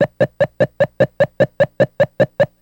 Video Game, Man Running Around Screen